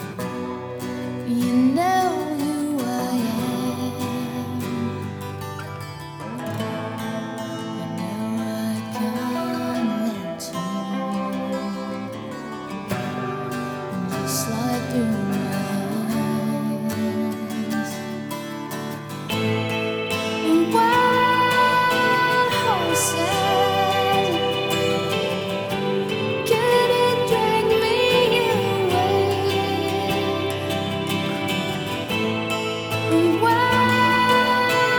# Adult Contemporary